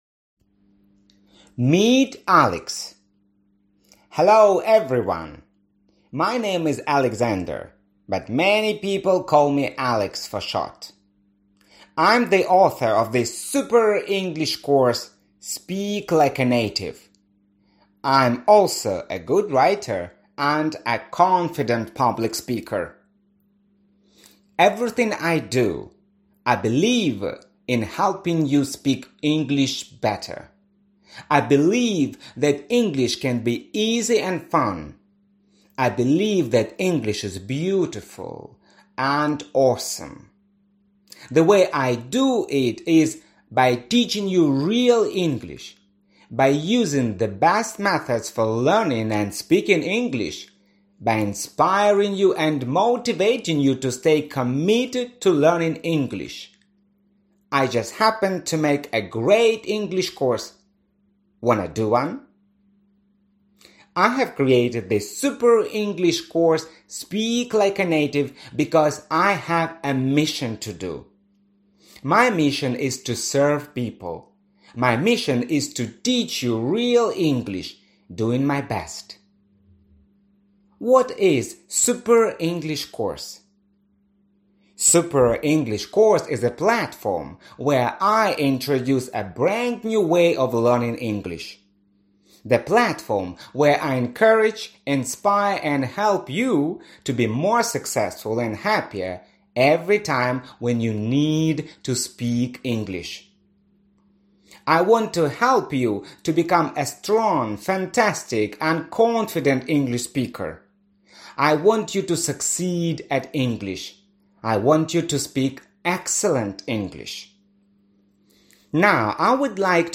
Аудиокнига Super English Course – Speak like a native | Библиотека аудиокниг
Прослушать и бесплатно скачать фрагмент аудиокниги